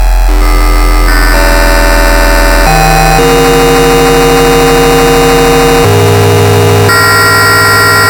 Во время написания программы для микроконтроллера было создано много смешных звуков, например эти:
scary-sounds-2